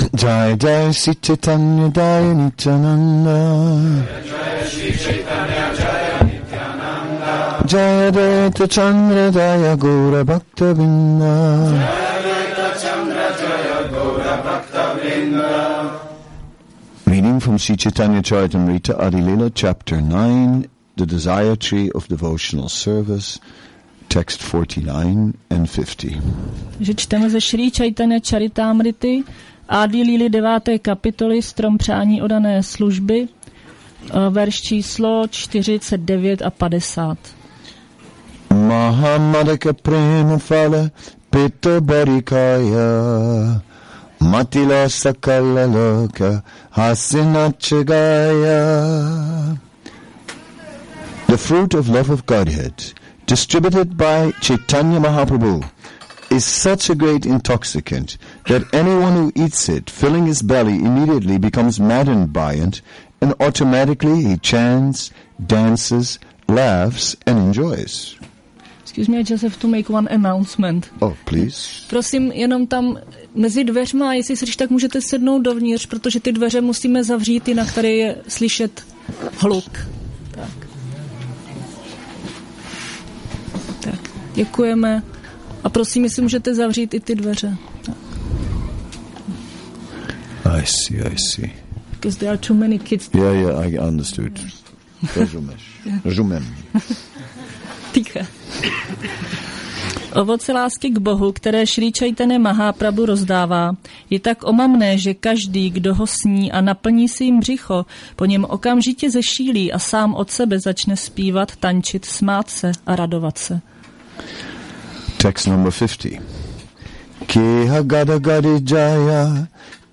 Šrí Šrí Nitái Navadvípačandra mandir
Přednáška CC-ADI-9.49-50